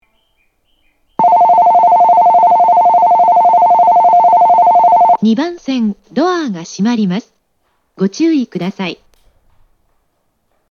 ROMベル
発車ベル
一度扱えば決まった秒数の間流れます。